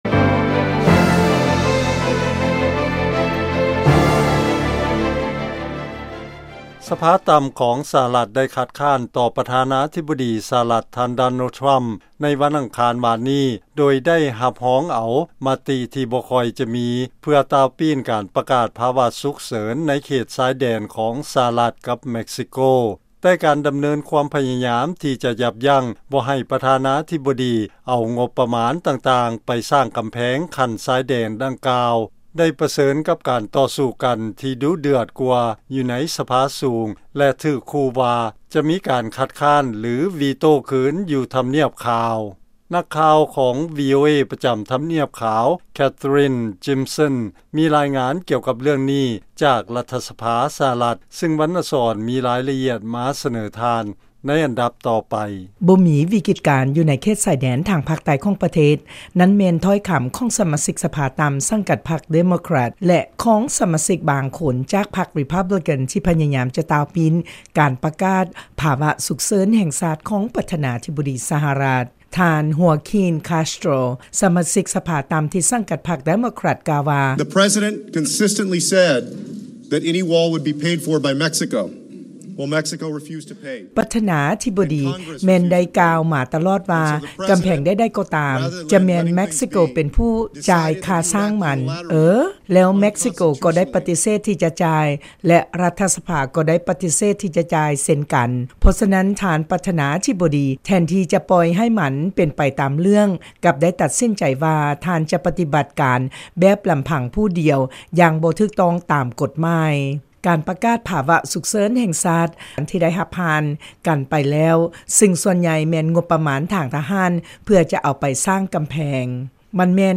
ລາຍງານກ່ຽວກັບໂຄງການກຳແພງຊາຍແດນສະຫະລັດ ກັບເມັກຊິໂກ